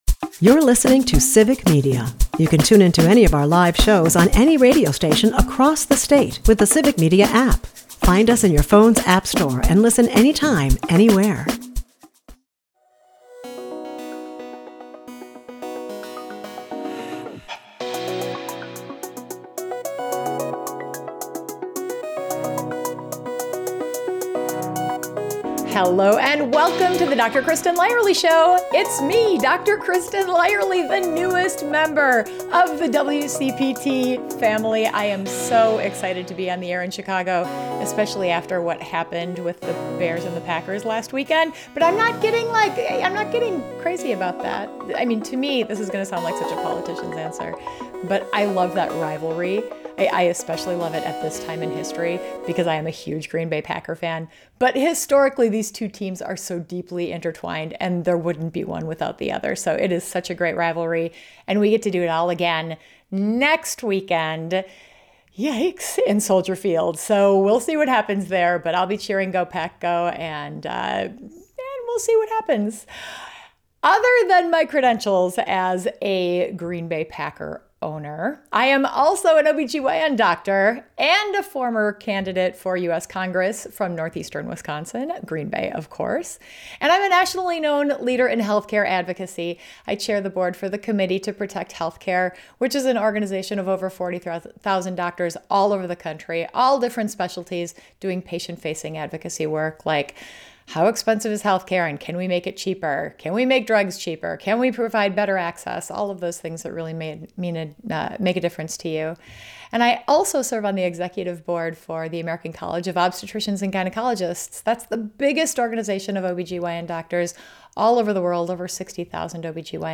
In this candid, entertaining conversation